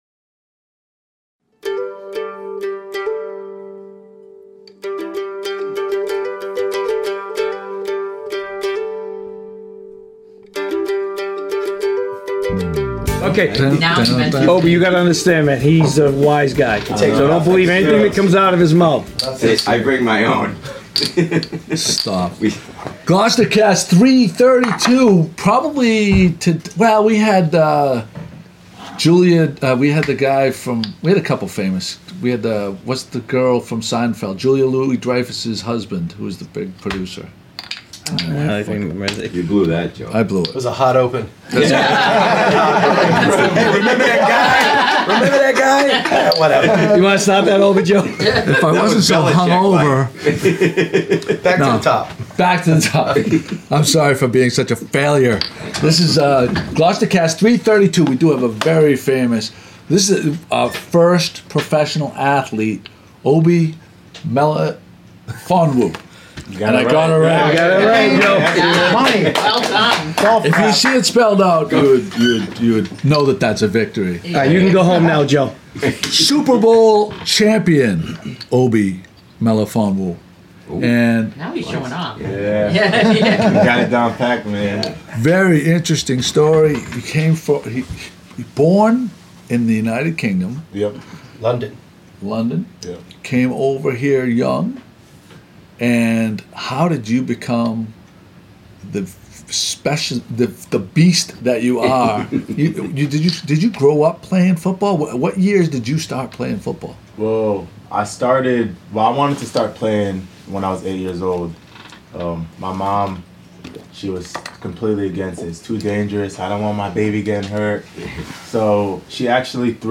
Interview With Patriots Safety Obi Melifonwu